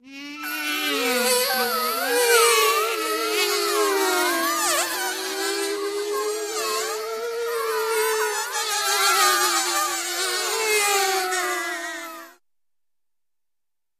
Several Different Insects Buzzing Around [stereo],.